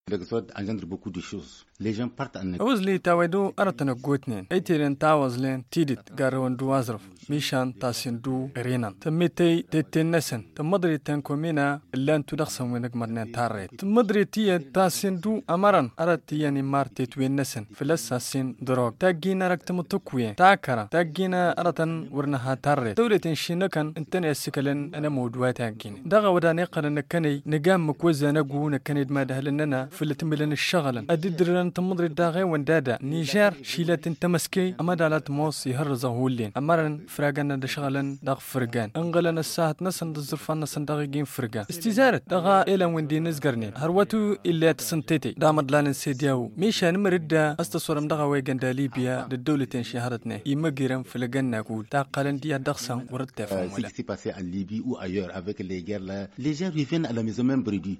Itahi Issoufou vice maire de la commune rurale de Tamaské dans le département de Keita, un adversaire de l’exode rural.
Magazine en tamasheq